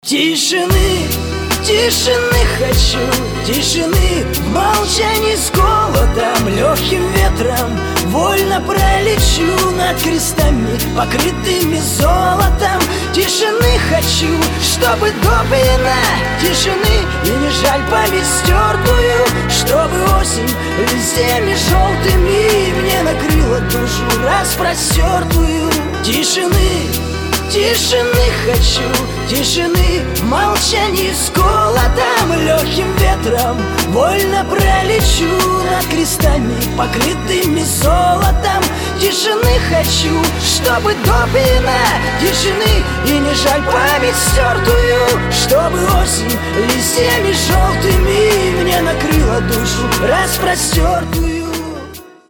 Рэп рингтоны , Рингтоны шансон
Грустные